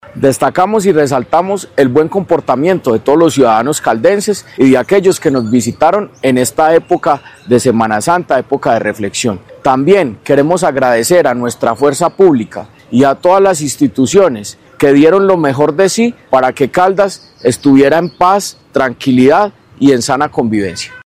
El secretario de Gobierno, Jorge Andrés Gómez Escudero, destacó que estos resultados obedecen al compromiso de la ciudadanía y al acompañamiento permanente de la Fuerza Pública.
art16290-Jorge-Andres-Gomez-Escudero-secretario-de-Gobierno-de-Caldas-Balance-Semana-Santa.mp3